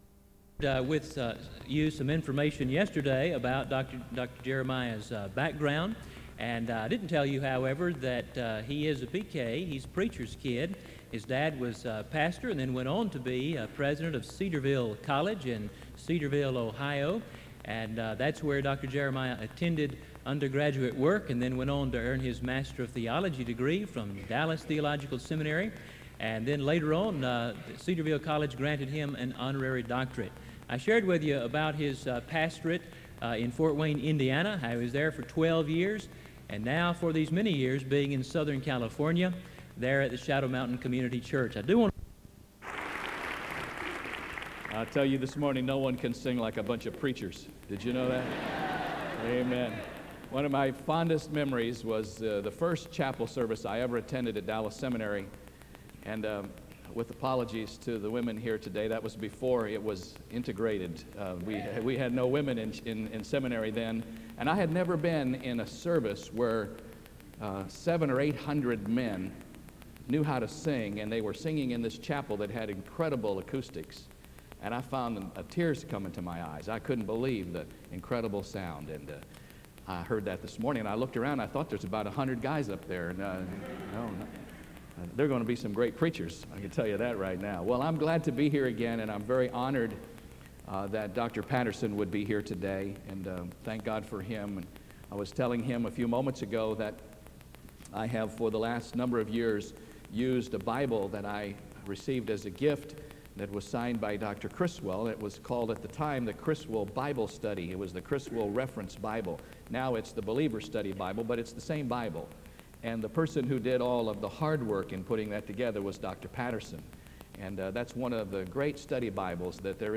SEBTS Chapel - David Jeremiah September 23, 1998
Dans Collection: SEBTS Chapel and Special Event Recordings SEBTS Chapel and Special Event Recordings - 1990s La vignette Titre Date de téléchargement Visibilité actes SEBTS_Chapel_David_Jeremiah_1998-09-23.wav 2026-02-12 Télécharger